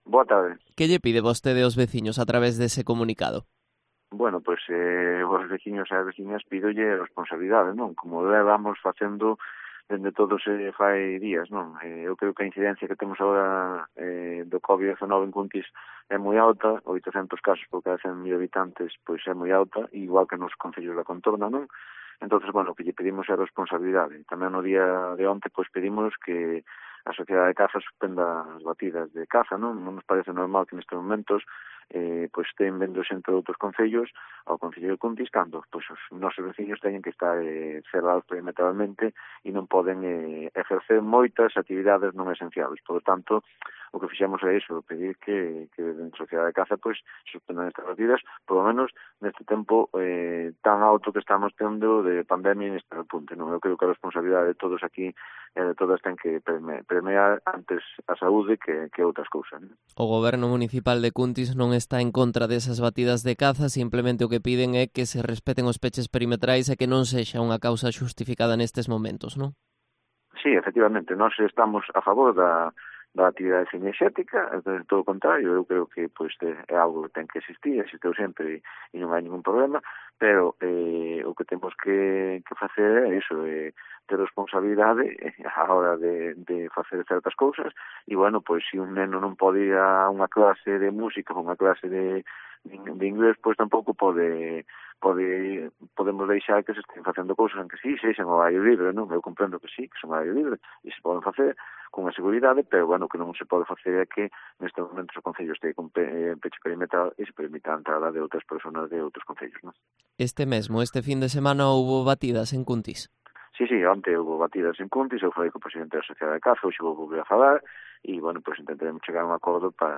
Entrevista a Manuel Campos, alcalde de Cuntis